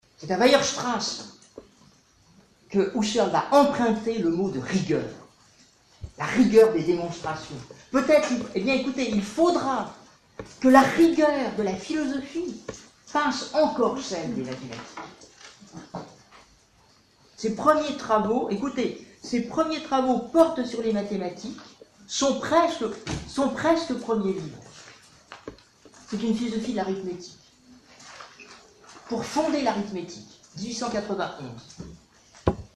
Mirari ² - Fichier "clipping_après.mp3"